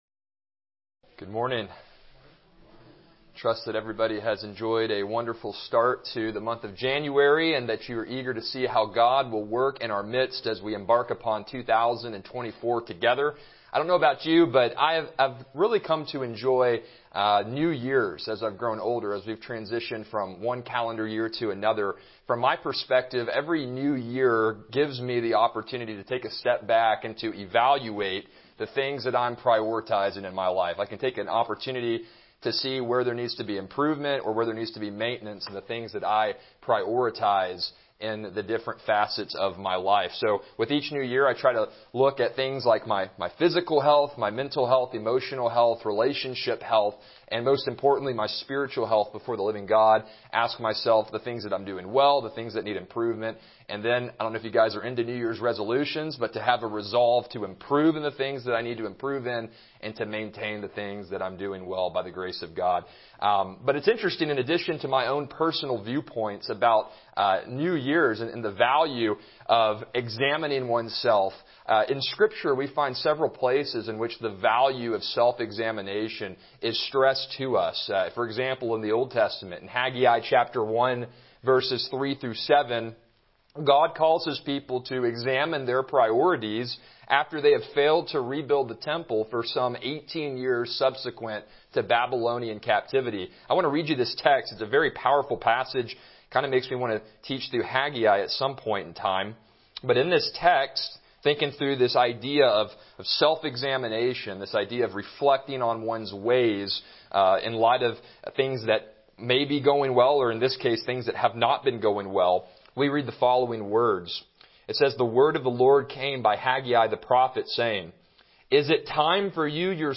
Passage: Psalm 1 Service Type: Sunday School « Defending the Historicity of Genesis 2 The Joy of Unity Through Persecution